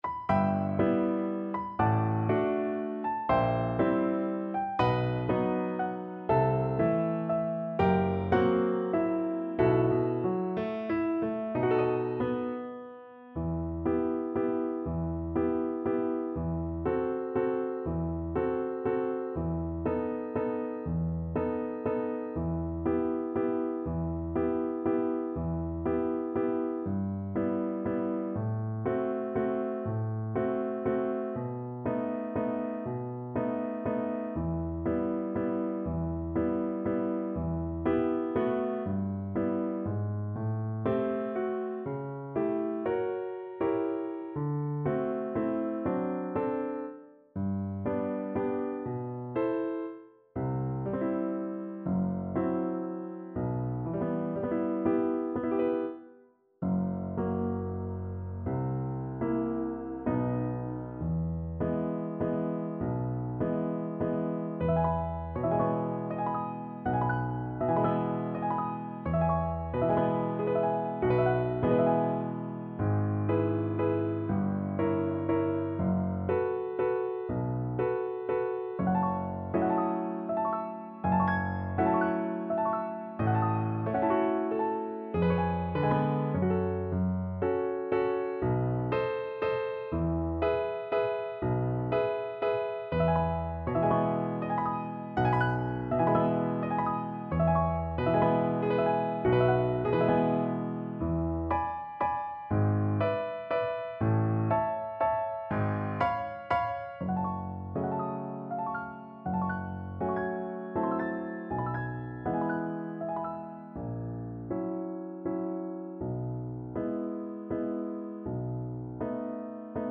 ~ = 120 Lento
3/4 (View more 3/4 Music)
Classical (View more Classical Violin Music)